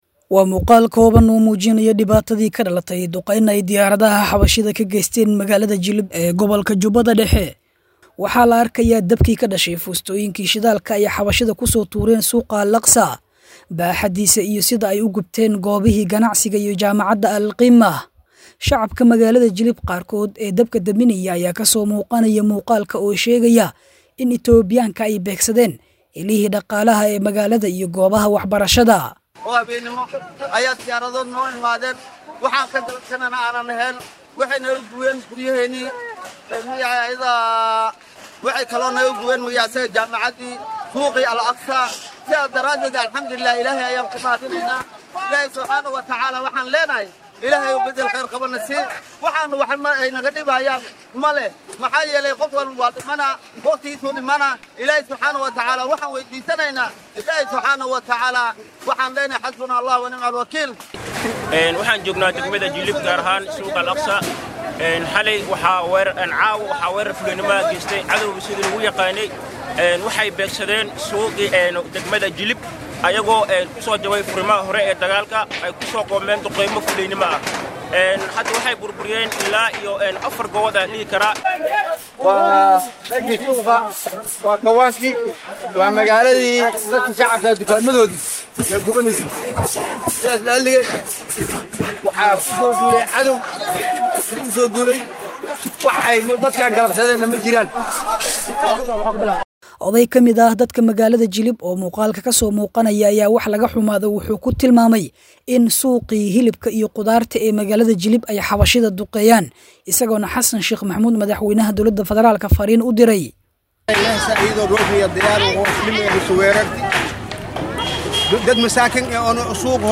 Muuqaal Muujinaya Duqeyntii Itoobiya ay ka Geysatay Jilib oo la Baahiyay.[WARBIXIN]